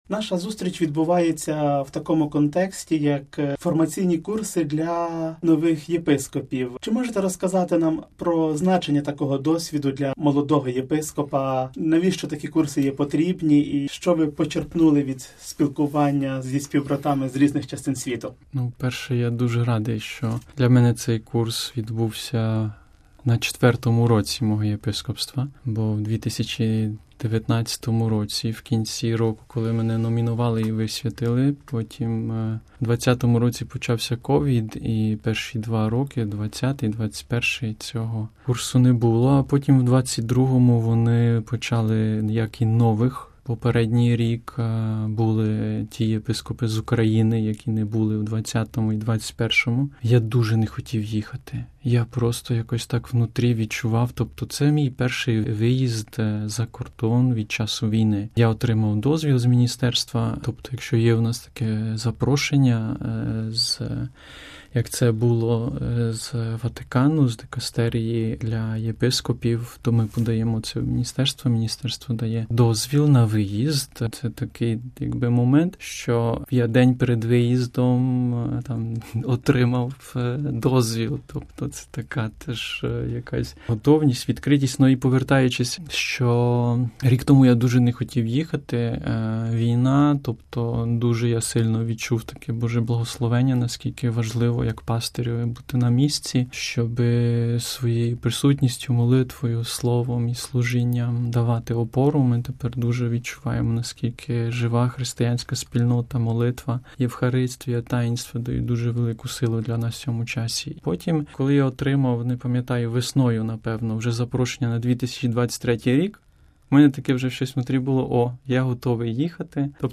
інтерв’ю з єпископом Миколою Лучком